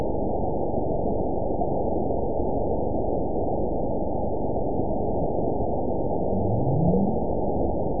event 917124 date 03/20/23 time 21:55:00 GMT (2 years, 1 month ago) score 9.66 location TSS-AB01 detected by nrw target species NRW annotations +NRW Spectrogram: Frequency (kHz) vs. Time (s) audio not available .wav